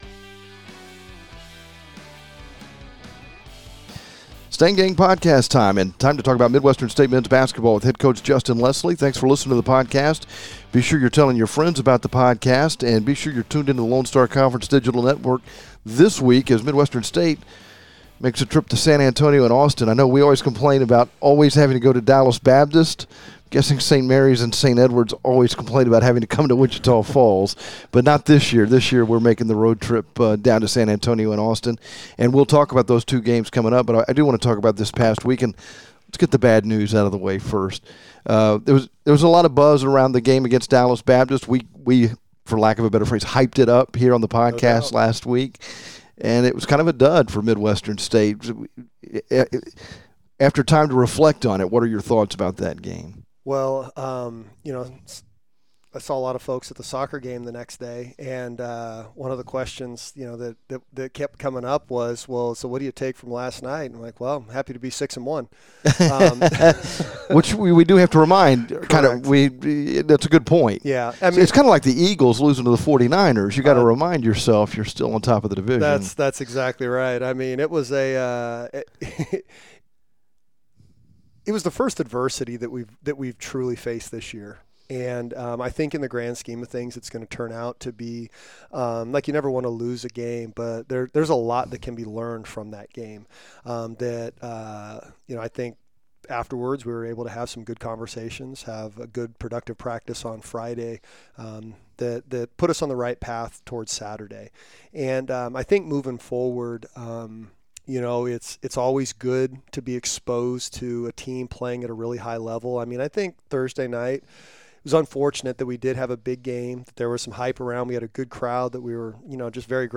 A candid conversation